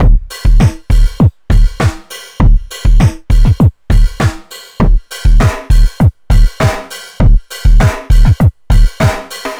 funkis 100bpm 02.wav